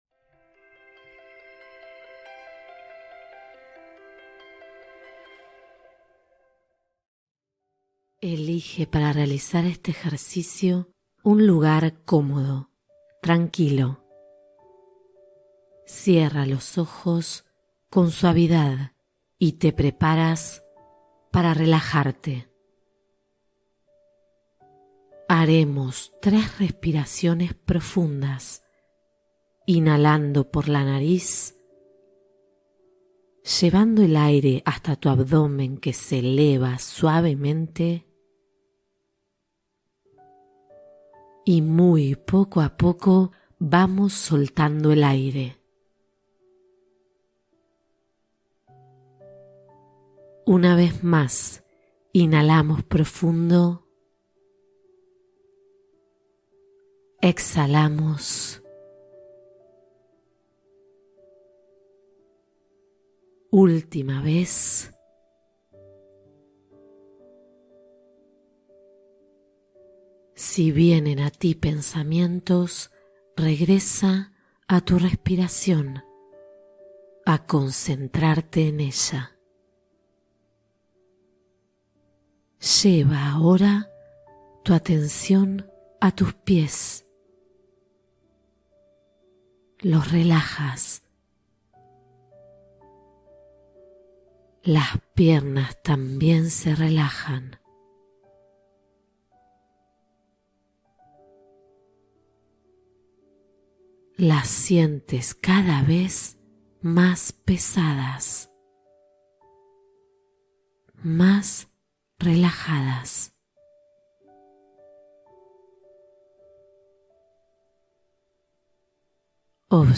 Activa tu Tercer Ojo: Meditación de Intuición y Claridad